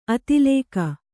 ♪ atilēka